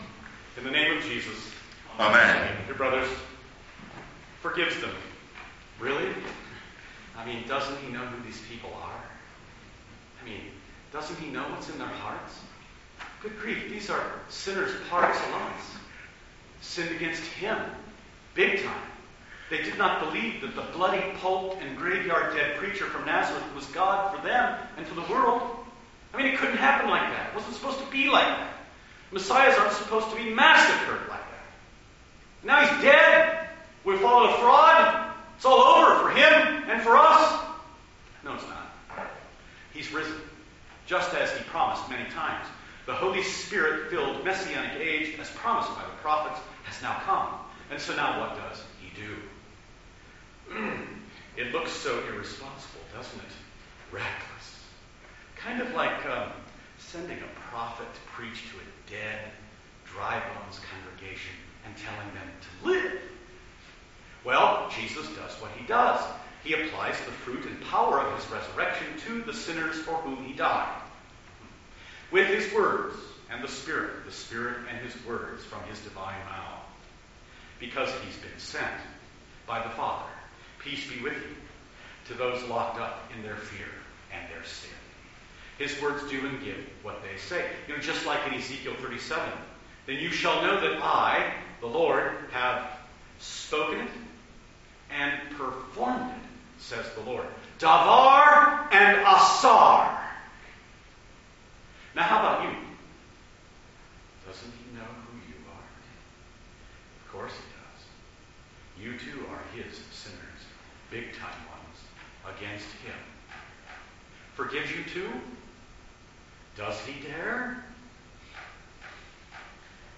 Due to the method of recording, the sound quality varies from file to file.
The sermon recordings are a little on the quiet side due to limitations of recording in the sanctuary.
Matins-2-Sermon.mp3